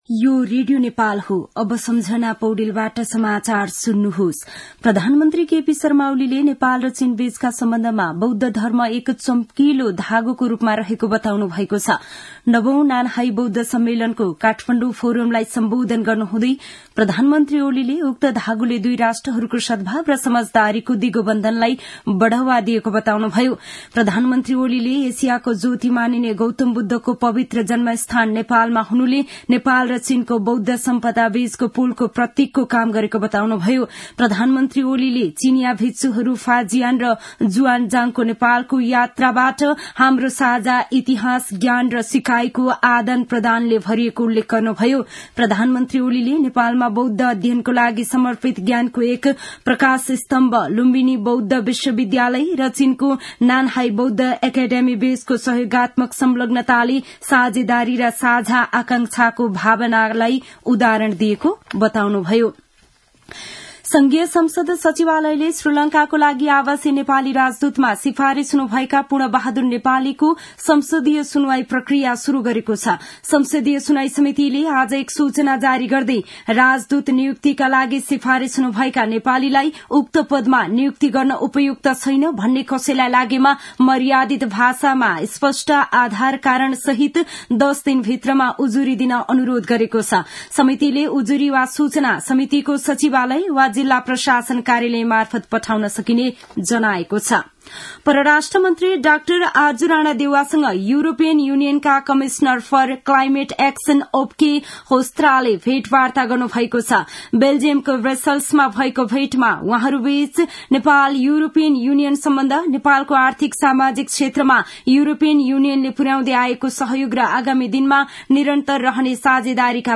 दिउँसो १ बजेको नेपाली समाचार : २९ मंसिर , २०८१
1-pm-nepali-news-1-11.mp3